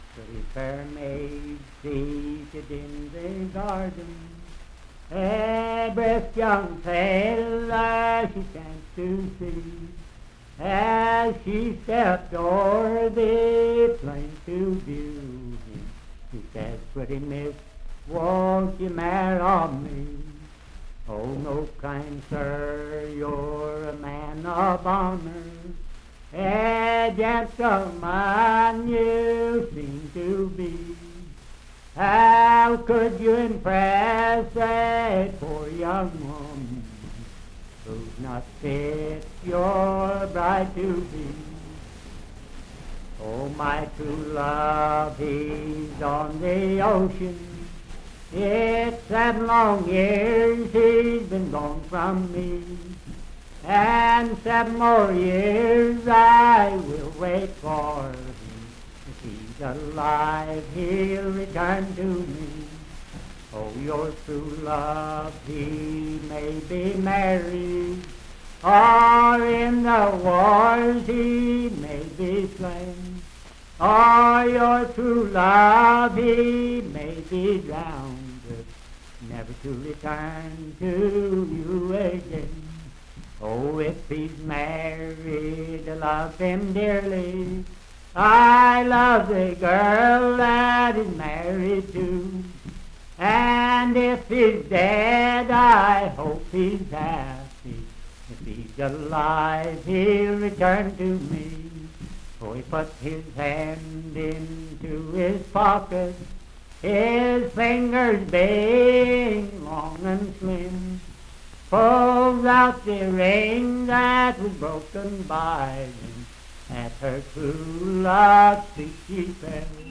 Unaccompanied vocal music and folktales
Voice (sung)
Wood County (W. Va.), Parkersburg (W. Va.)